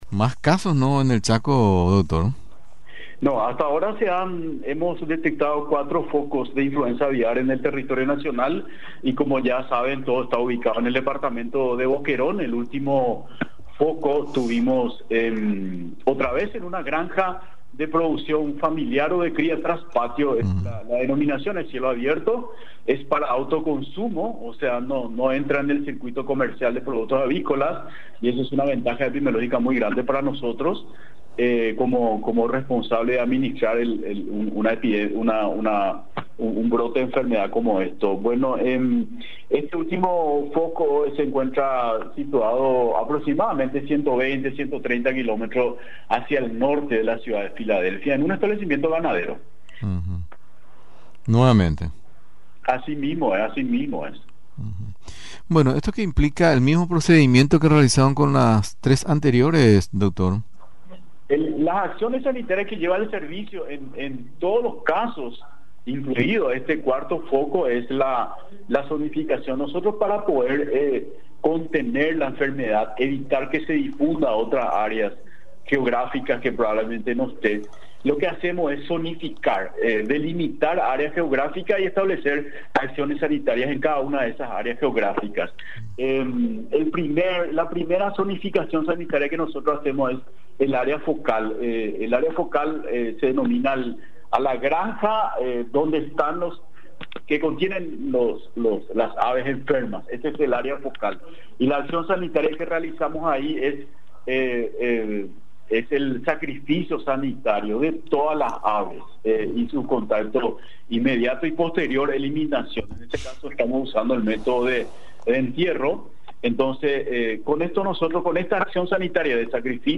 En entrevista con Radio Nacional del Paraguay